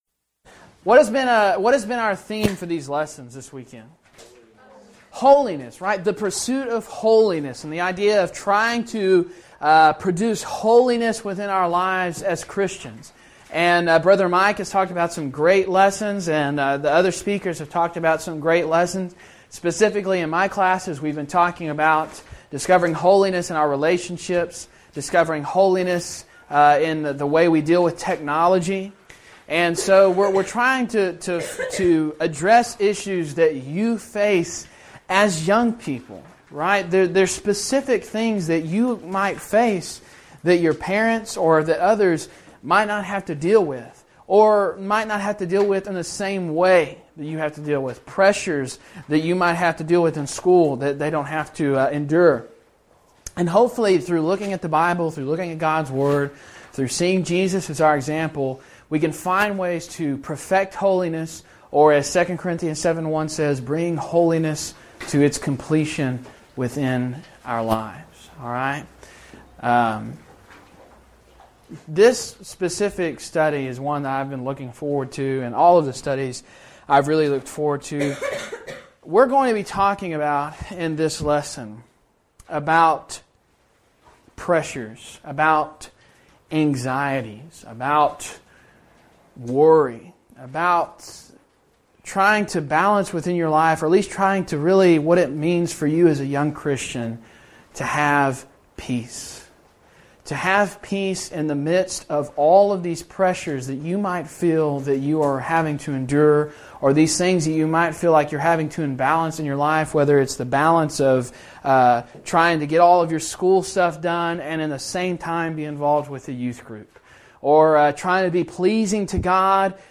Event: Discipleship University 2012 Theme/Title: The Privilege, Pattern, & Pursuit of Holiness
lecture